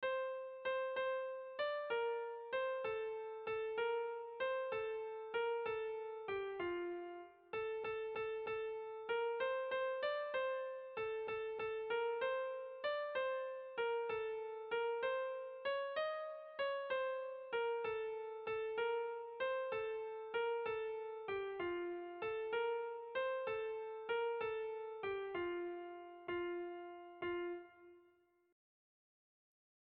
Irrizkoa
ABD